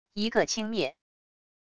一个轻蔑wav音频